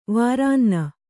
♪ vārānna